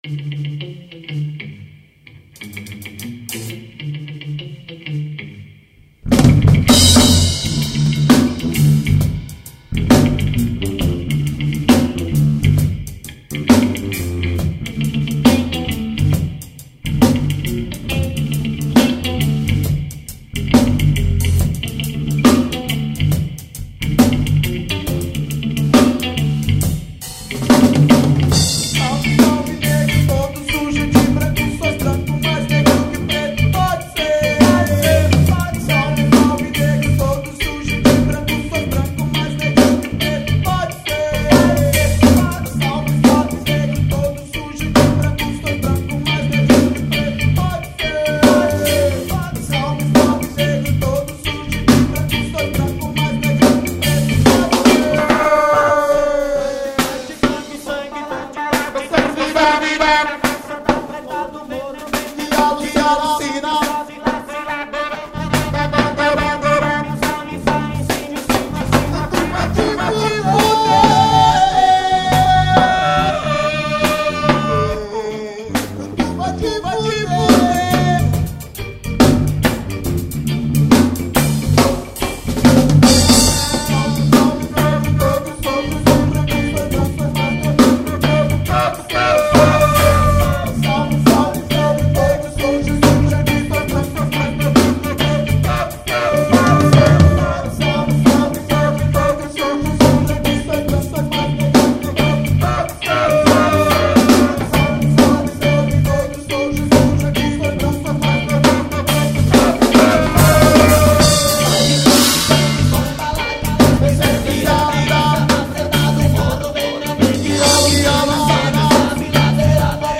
05:01:00   Reggae